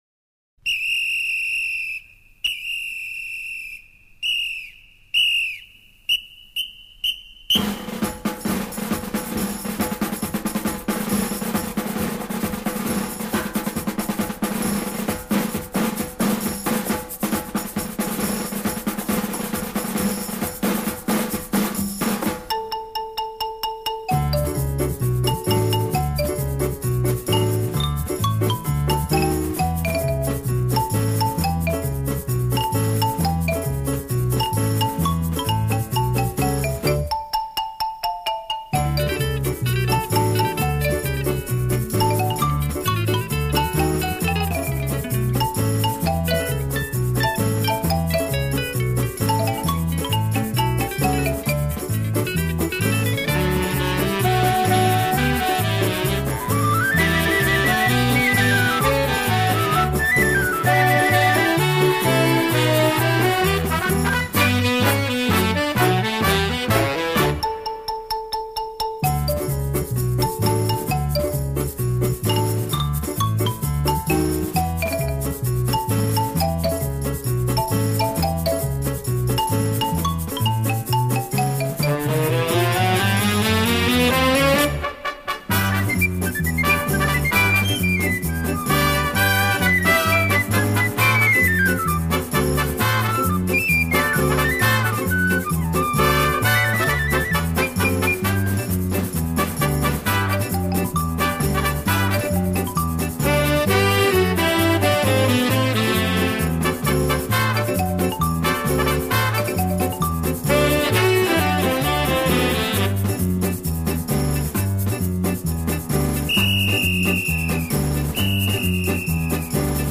В начале исполнения такой характерный свист.